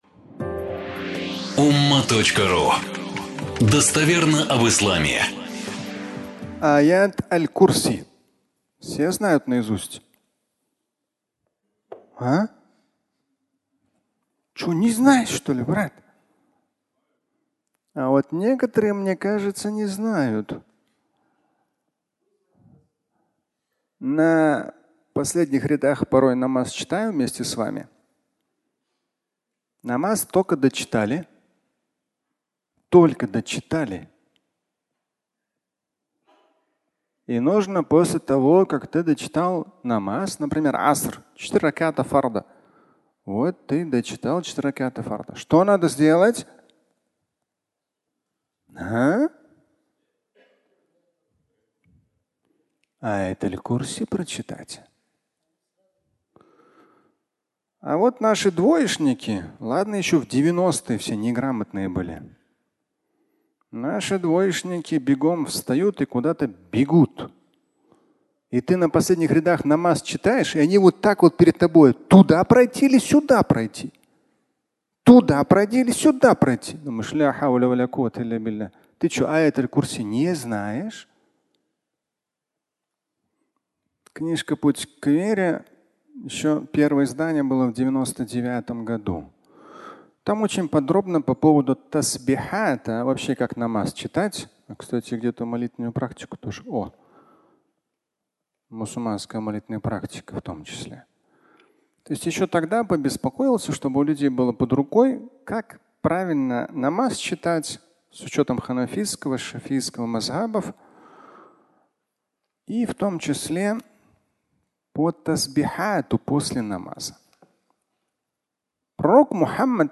Аят «аль-Курси» (аудиолекция)